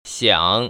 [xiǎng]
시앙